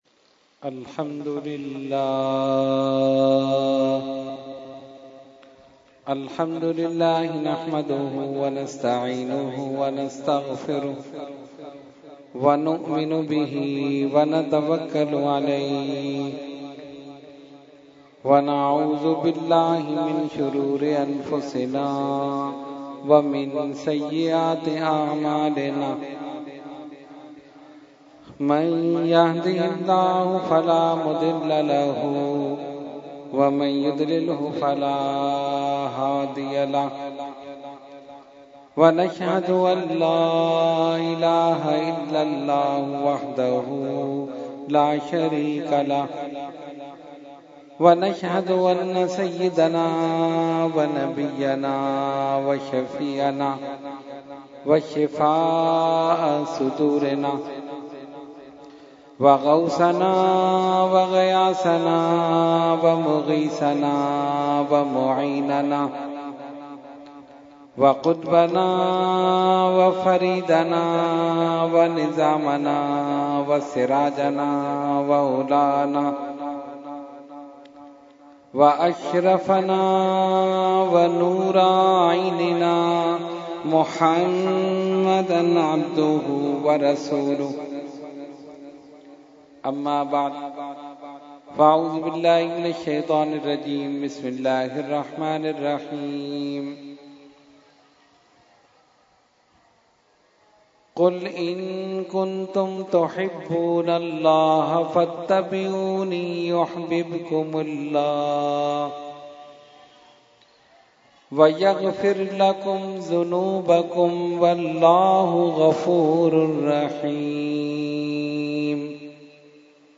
Category : Speech | Language : UrduEvent : Muharram 2018